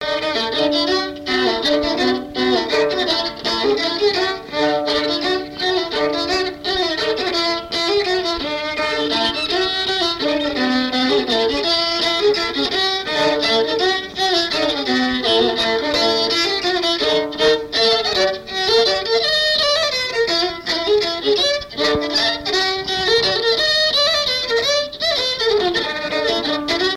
danse : mazurka
Pièce musicale inédite